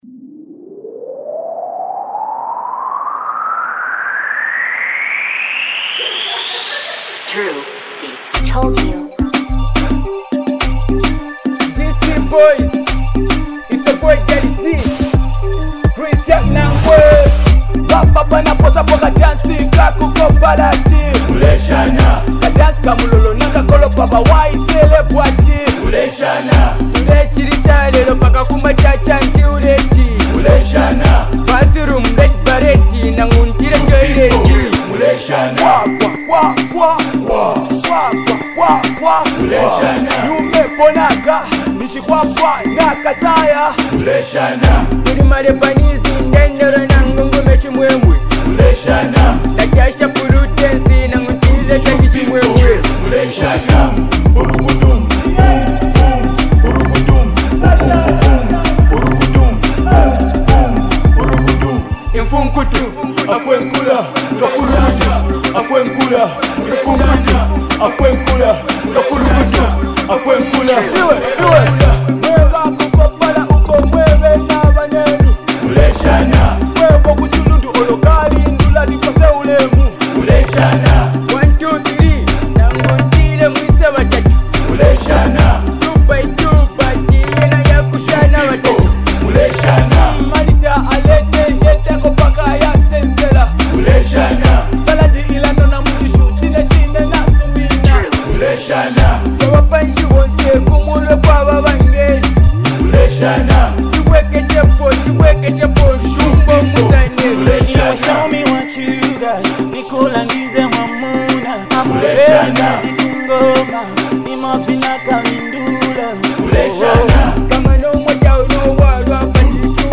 Genre: Dunka